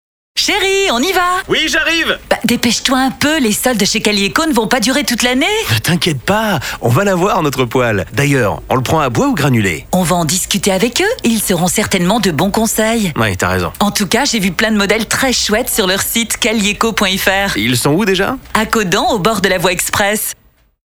Spot radio diffusé en Janvier.